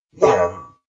Skel_COG_VO_grunt.ogg